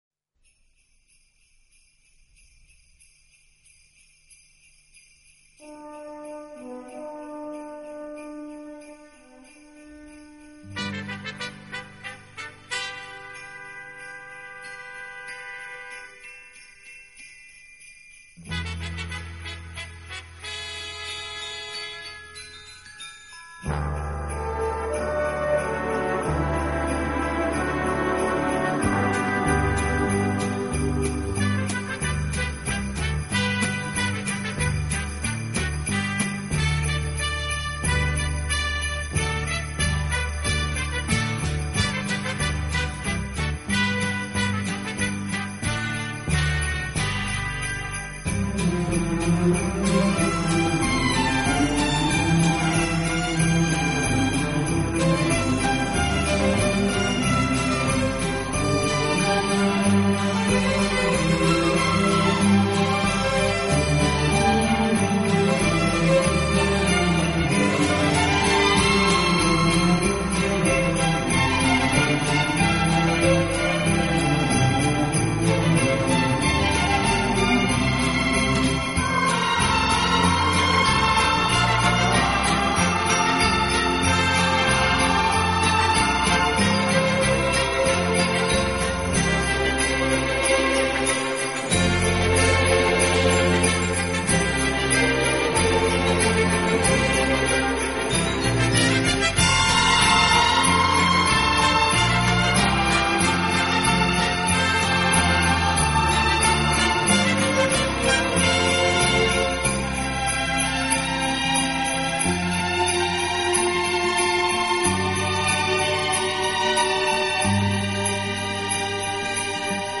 此外，这个乐队还配置了一支训练有素，和声优美的伴唱合唱队。